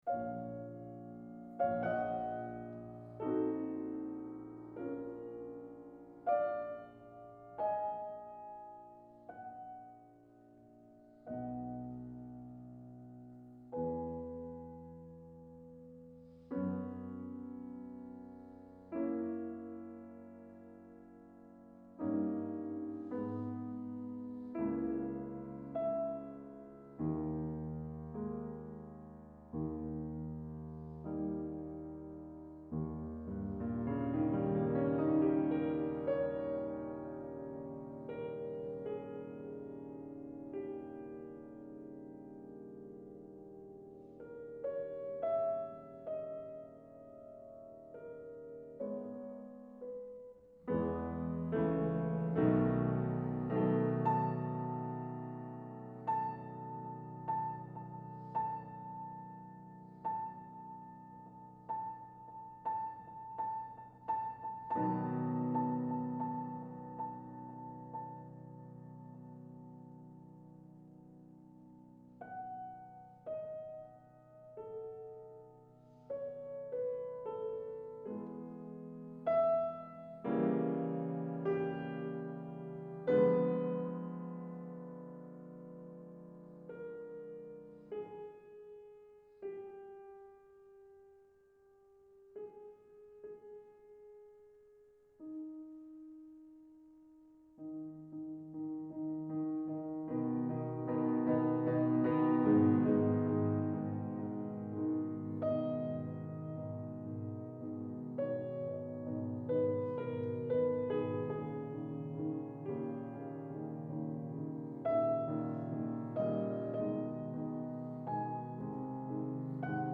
Coulées félines, rageuses dans certains passages, pattes de velours espiègles dans quelques autres.
Tout est ici « classique » pour que la musique se diffuse dans une acoustique parfaite comme l’allégorie peinte le signifie. 600 mélomanes un peu excentriques écoutent ce soir-là la pianiste sur des sièges en tissu rouge très poussiéreux.